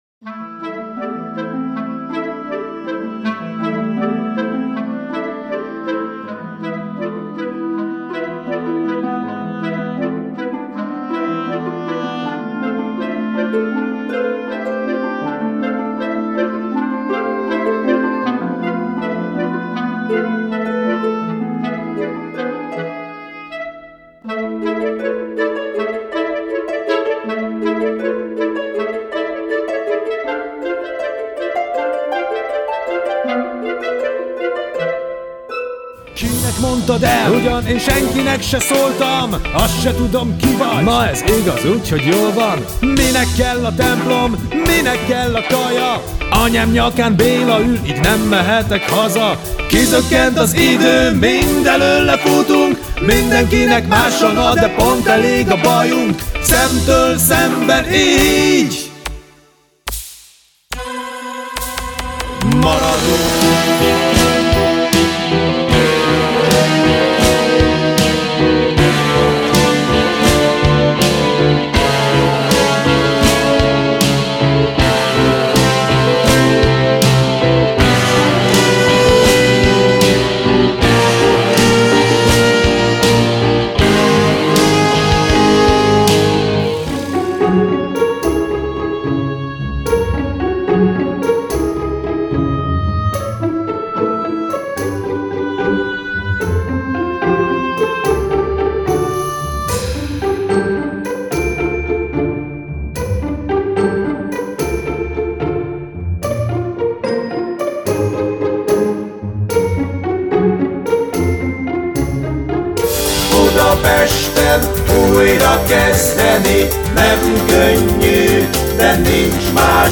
ifjúsági musicalt Böszörményi Gyula nagy sikerű regényéből.
- NÉHÁNY RÉSZLET A DARAB ZENÉJÉNEK DEMÓJÁBÓL -